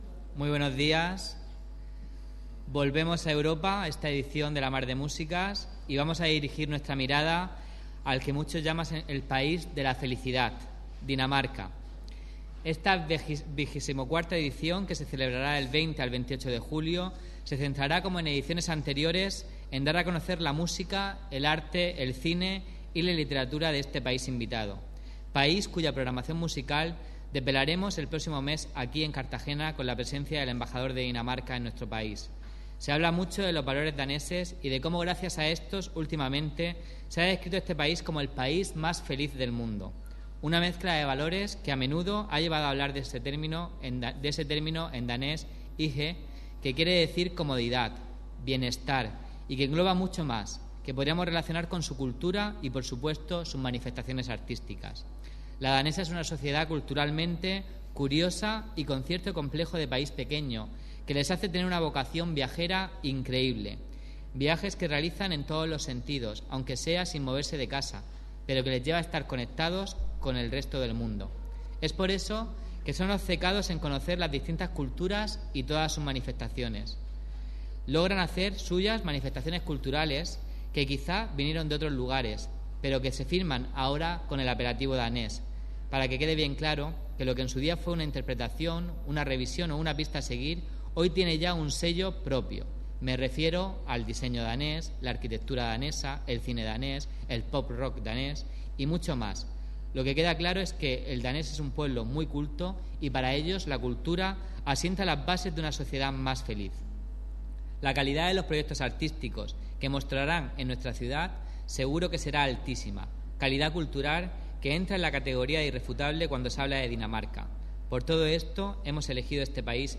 Audio: Rueda de prensa presentaci�n del pa�s invitado a La Mar de M�sicas (MP3 - 16,73 MB)